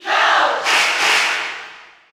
Category: Crowd cheers (SSBU) You cannot overwrite this file.
Cloud_Cheer_French_PAL_SSBU.ogg